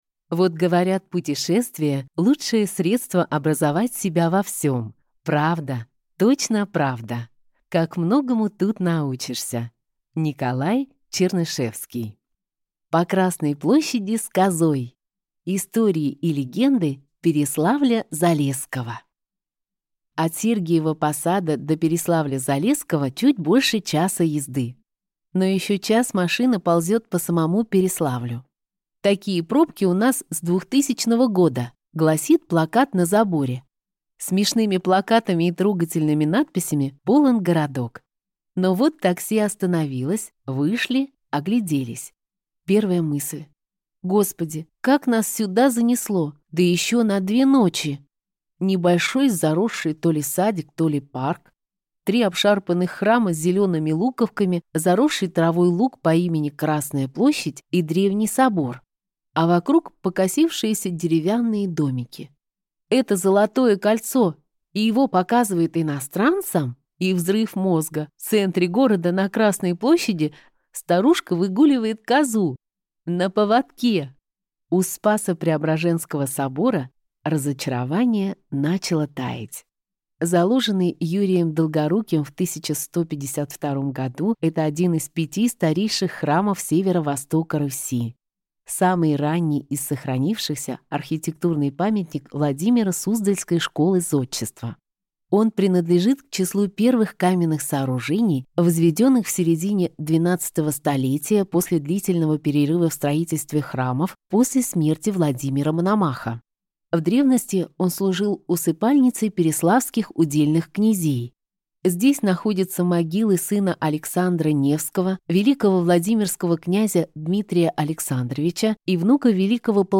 Аудиокнига К России с любовью! В поисках тишины, восходов и изумрудного варенья | Библиотека аудиокниг